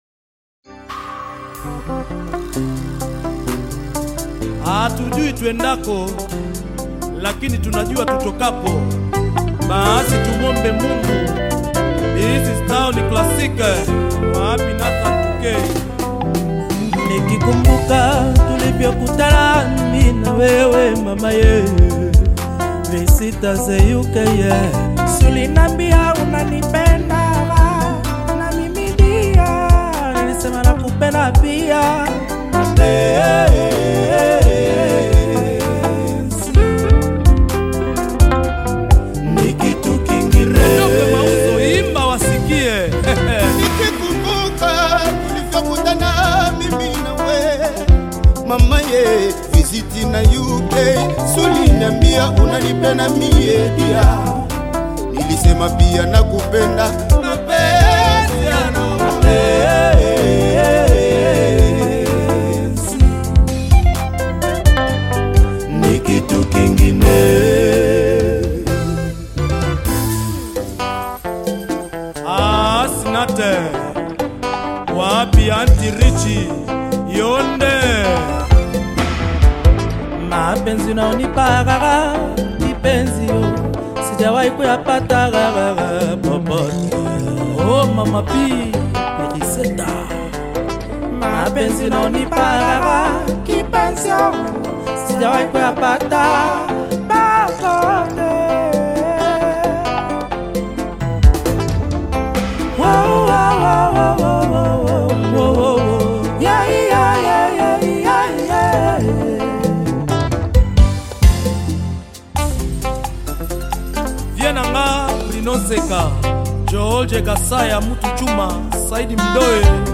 Tanzanian Bongo Flava Rhumba Band
a soulful and emotionally charged Rhumba ballad